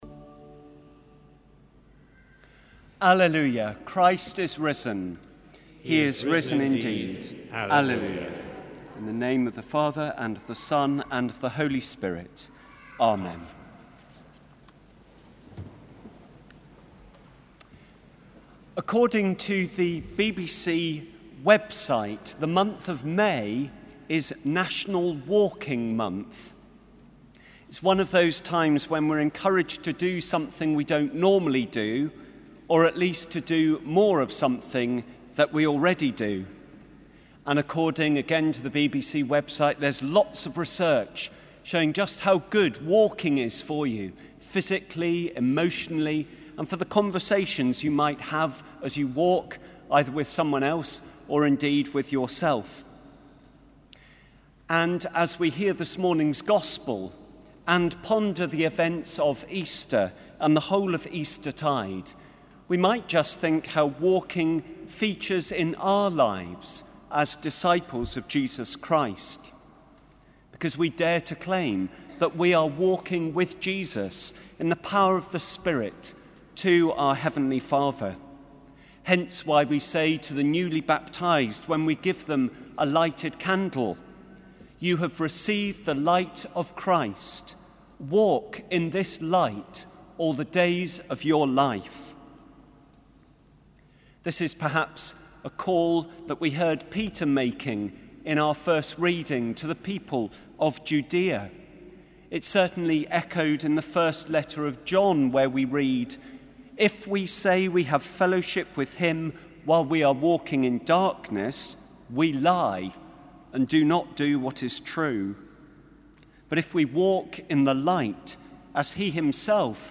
Sermon: Walking in the Light
Sermon: Walking in the Light Preacher: Date: Sunday 4th May 2014 Service: Cathedral Eucharist Listen: Download Recording (MP3, 13.4M) According to the BBC website May is National Walking Month in the UK.